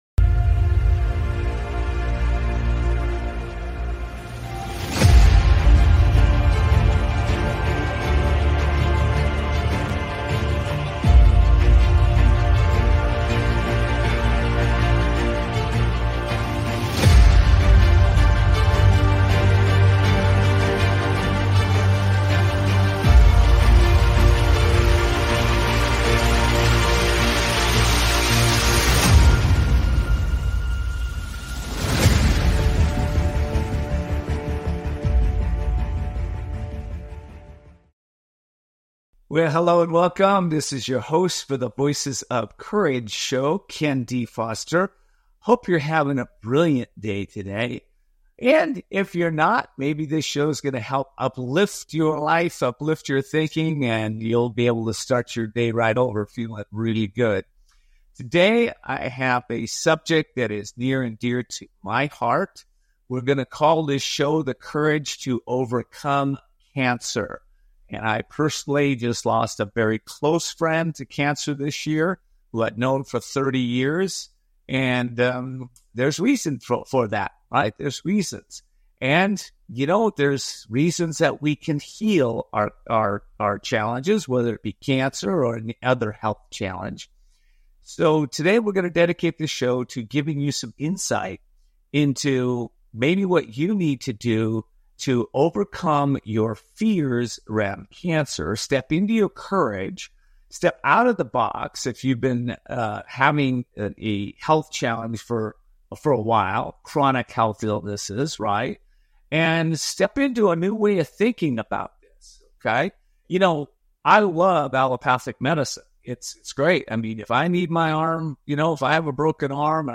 Voices of Courage is a power packed radio, podcast and TV show, where audiences engage to grow their business and transform their life’s. The 60-minute weekly shows are provocative, entertaining and transformational. Audiences are challenged to see the unseeable, know the unknowable and do the impossible.